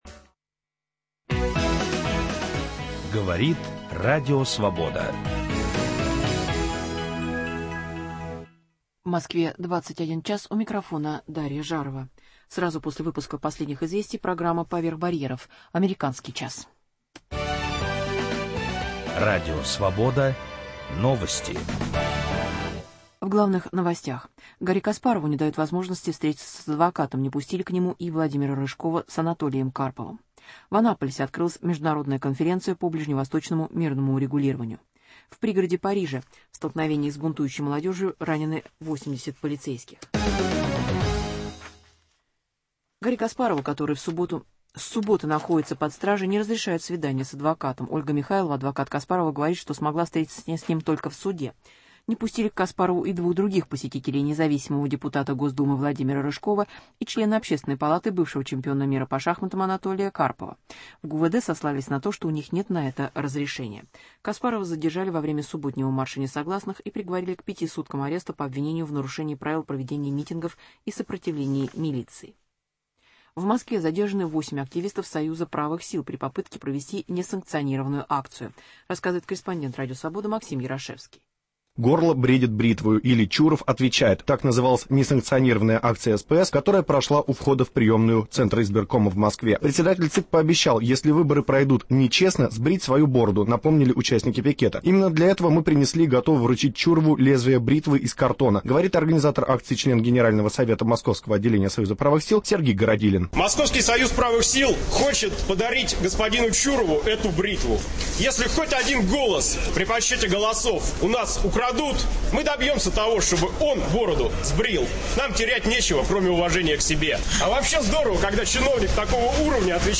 Интервью.
Репортаж.